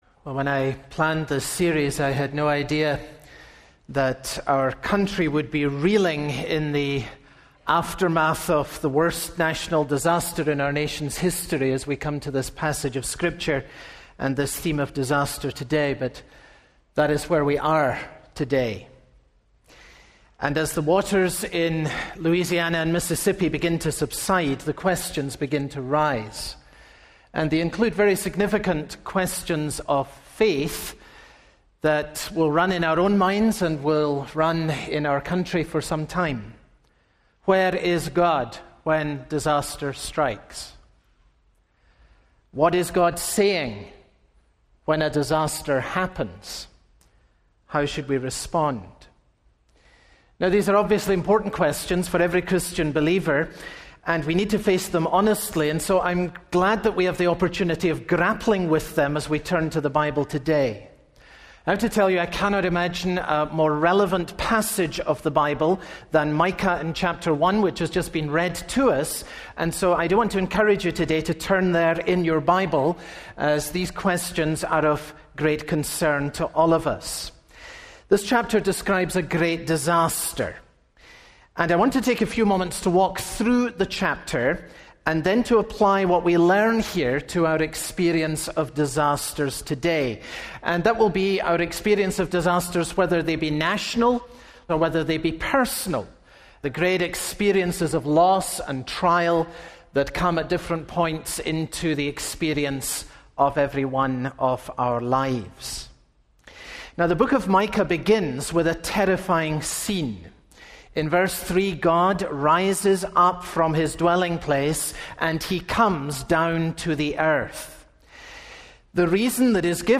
Disaster comes to both the godly and the ungodly, but where does it come from? Find out in this sermon.